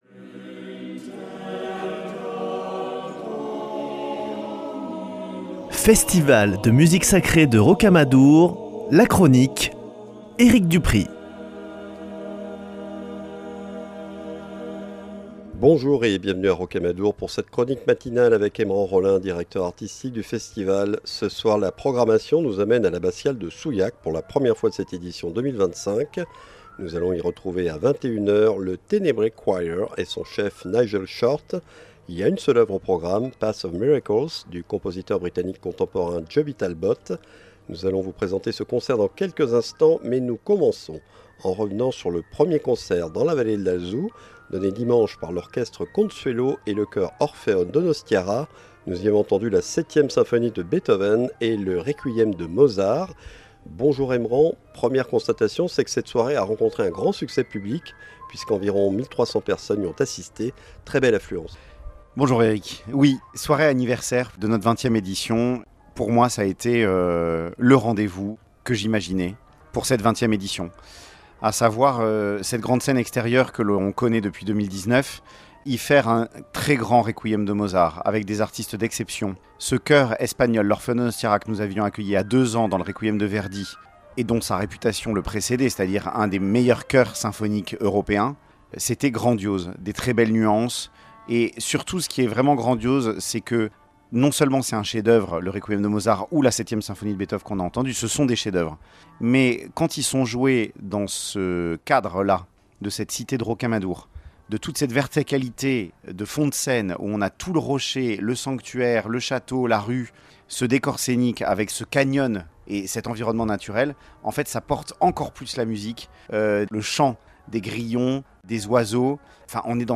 Chronique Rocamadour